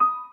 piano_last20.ogg